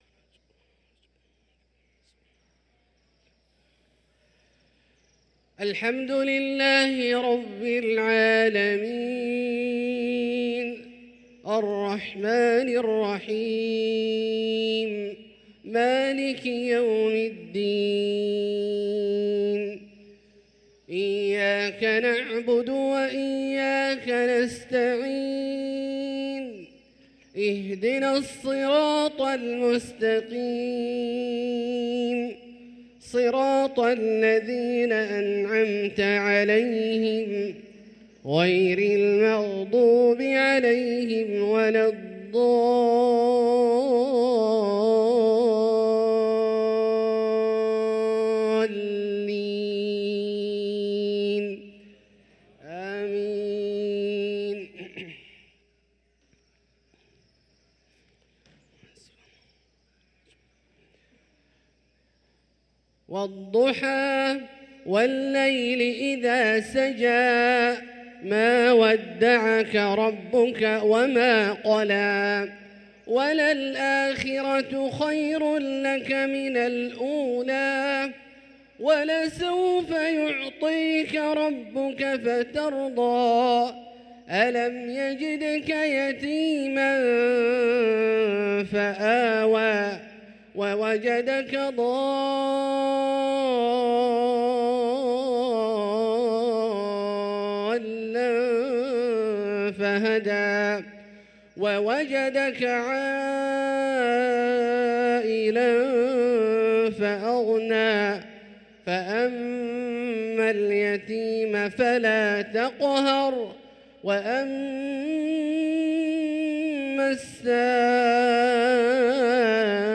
صلاة المغرب للقارئ عبدالله الجهني 24 جمادي الآخر 1445 هـ
تِلَاوَات الْحَرَمَيْن .